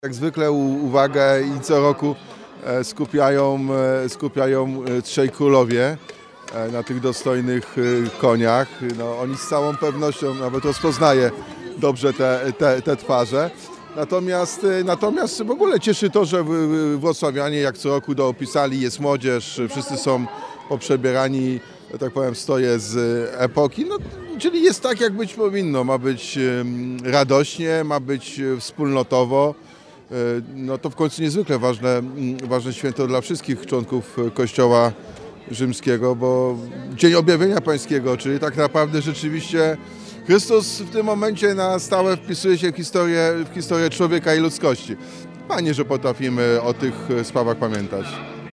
Po raz 13. przez Wrocław przeszedł Orszak Trzech Króli.